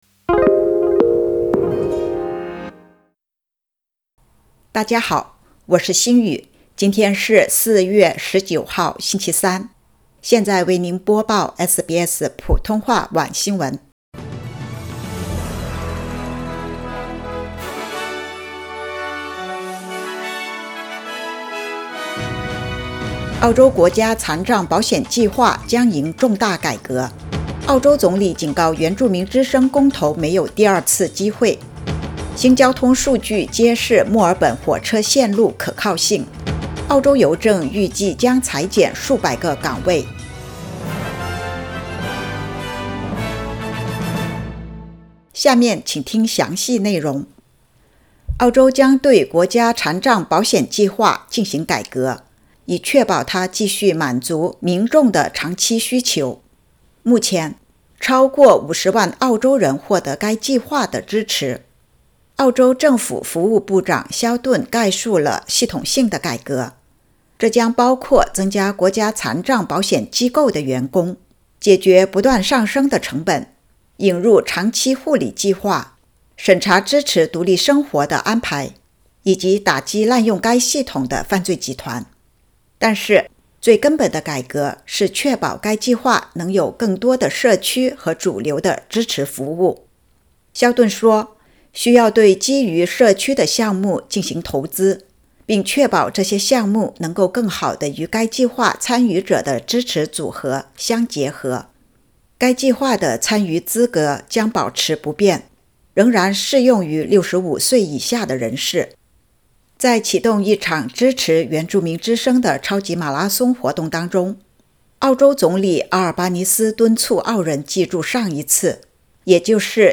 SBS晚新闻（2023年4月19日）
SBS Mandarin evening news Source: Getty / Getty Images